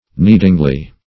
kneadingly - definition of kneadingly - synonyms, pronunciation, spelling from Free Dictionary Search Result for " kneadingly" : The Collaborative International Dictionary of English v.0.48: Kneadingly \Knead"ing*ly\, adv.